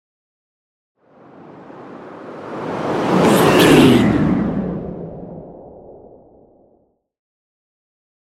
Whoosh airy long
Sound Effects
futuristic
tension
whoosh